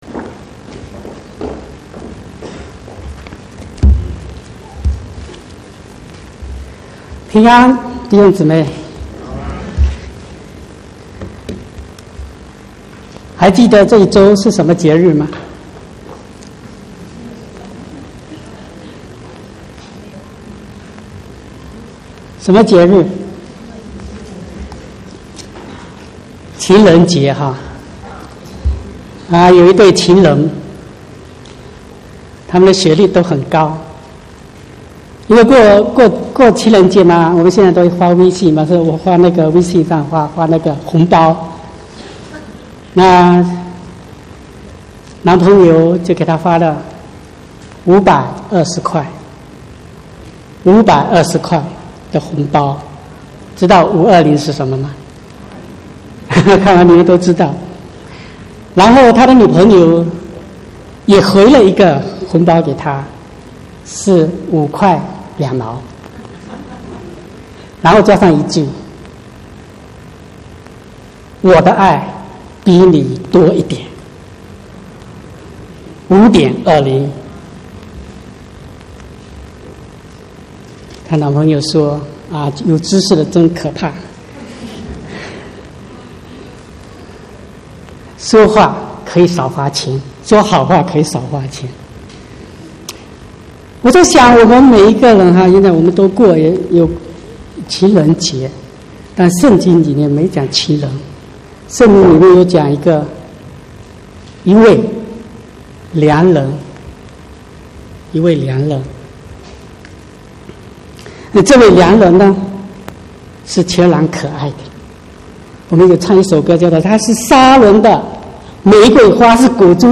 17/2/2019 國語堂講道